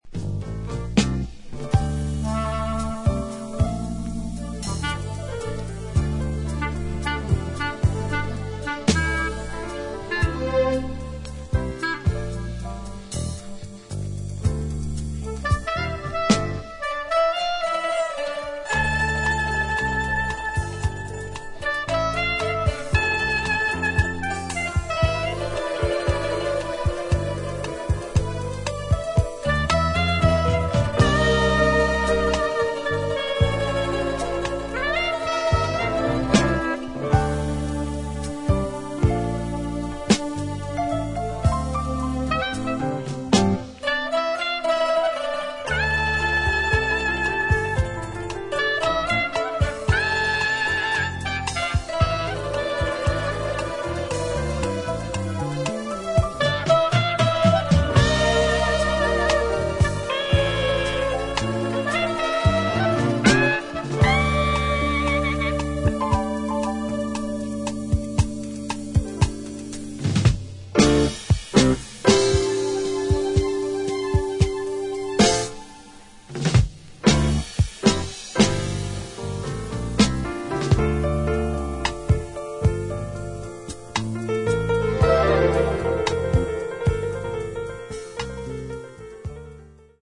バレアリック・古典ナンバーとしてハウス、ディスコDJから支持され続けている大人気ダンス・ナンバー。